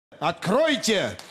Звук Якубовича говорит Откройте